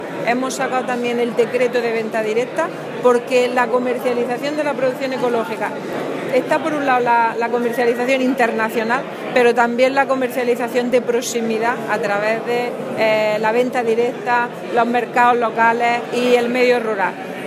Carmen Ortiz ha pronunciado estas palabras en Sevilla durante la clausura de la cuarta edición de la Conferencia Europea sobre elaboración de productos ecológicos, organizada por primera vez en España por la asociación europea de la Federación Internacional de la Agricultura Ecológica (Ifoam-UE) y la Asociación Valor Ecológico-Ecovalia.
Declaraciones consejera sobre sector